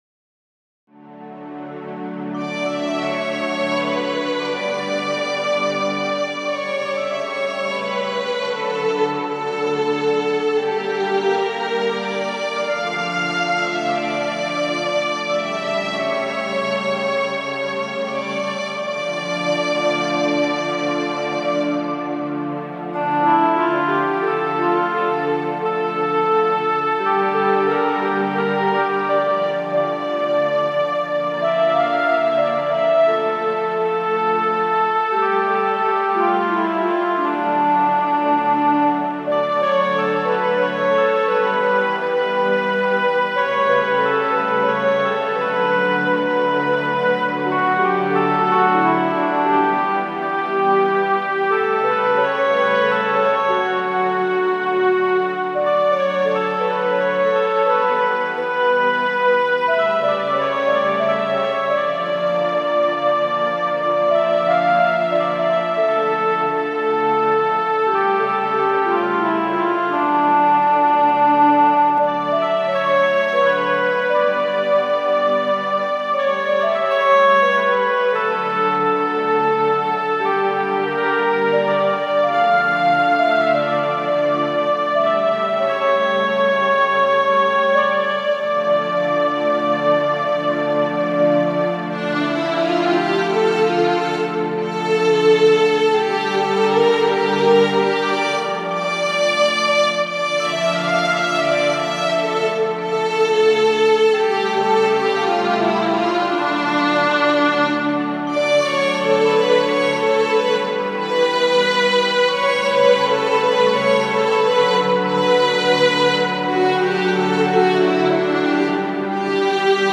Przedstawiam moje kolejne nagranie na Roland JUNO-DS oraz na KORG Wavestate.
Moja wersja jest idealnie zgodna z nutami, które posiadam.